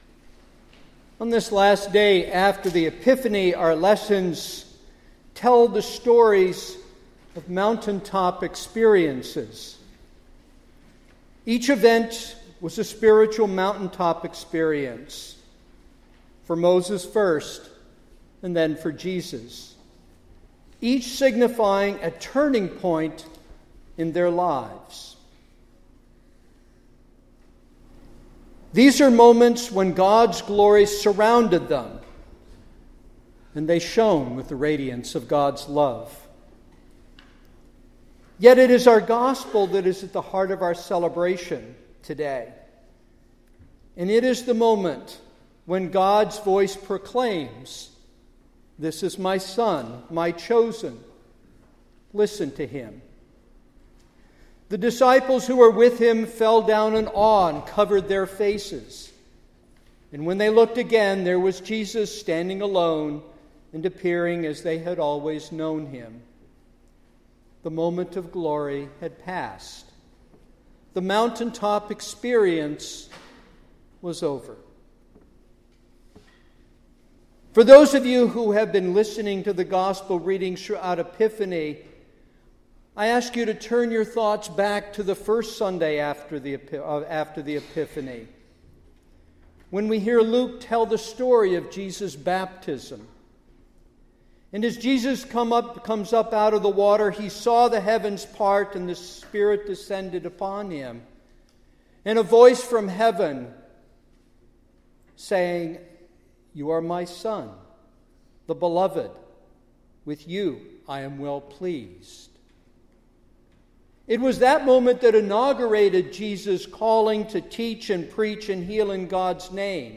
2016 Sunday Sermon